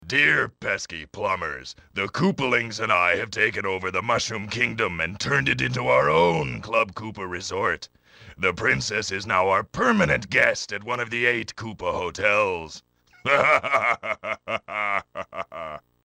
Unused voice sample of Bowser in Hotel Mario.
HM_Bowser_unused_voice_sample.oga.mp3